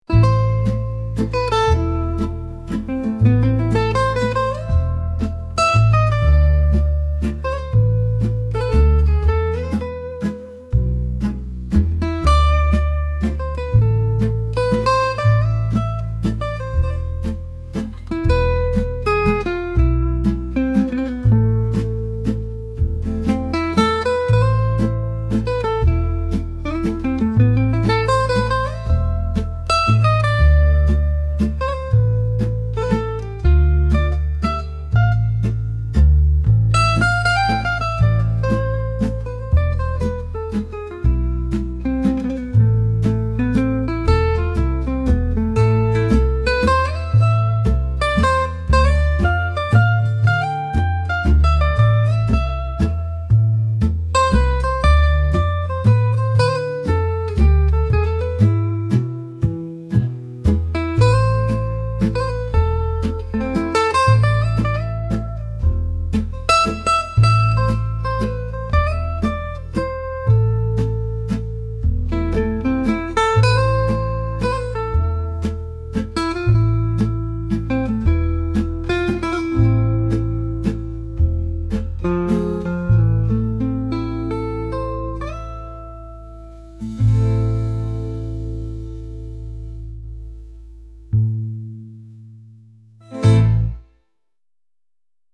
[Listen to the faint voice of the parakeet. What did you hear?}
Location: Santa Monica Mountains, CA
interspecies communication, geostorms, solar events, birds, Nanday parakeets, incoming light,